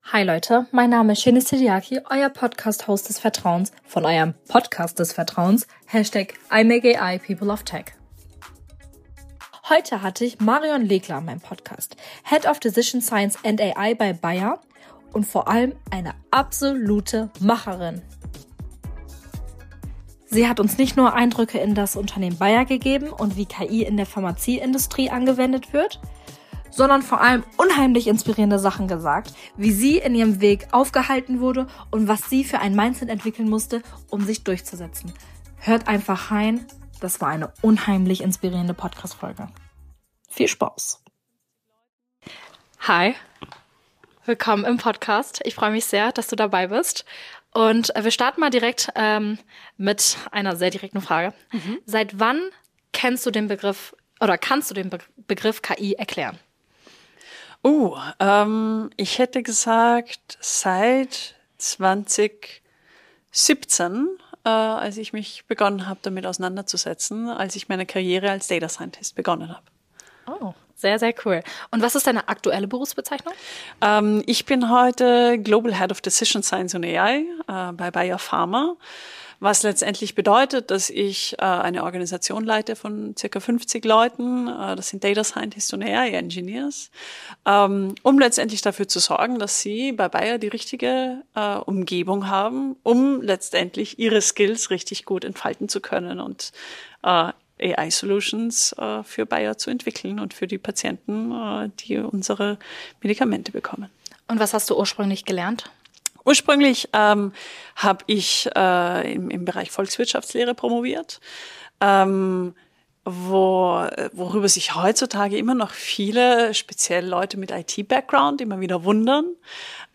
Mit vielen inspirierenden Tipps für junge Frauen, die in Technologie, Wissenschaft und Innovation ihren Weg gehen möchten, ist diese Episode ein Gespräch über Mut, Chancen und die Zukunft von KI.